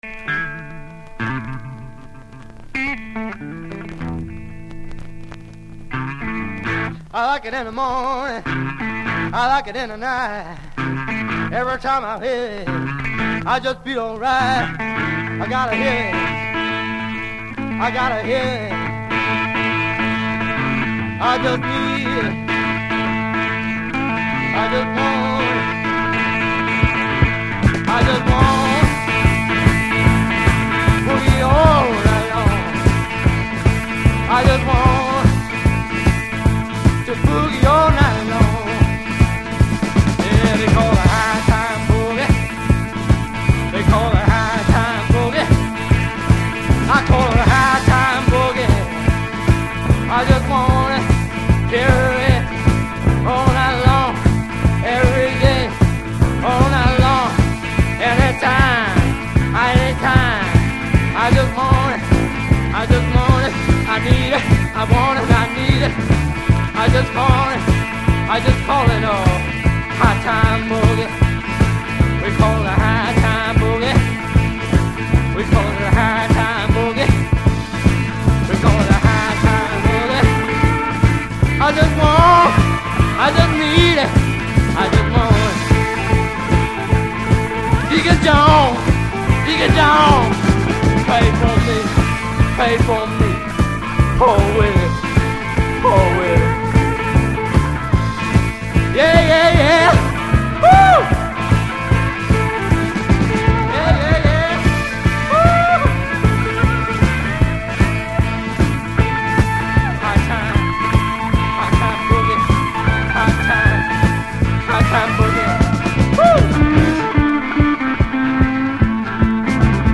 Rock blues